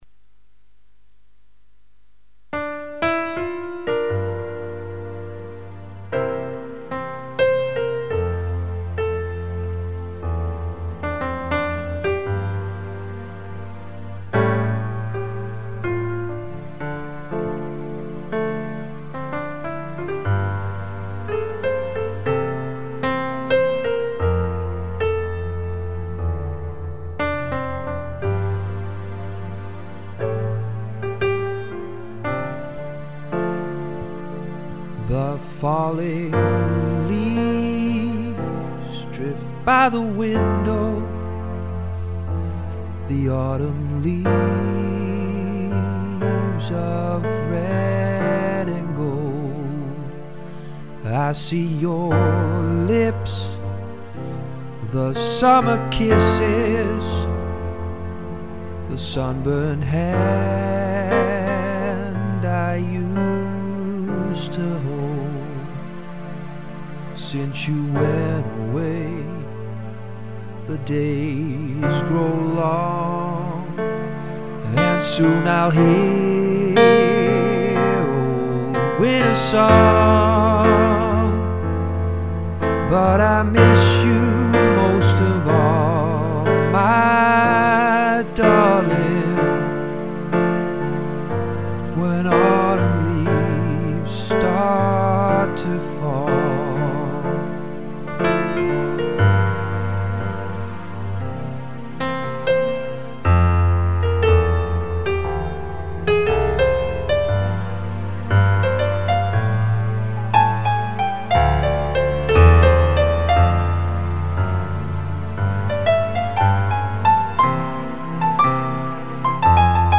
alles zelf ingespeeld/gezongen....kritiek graag!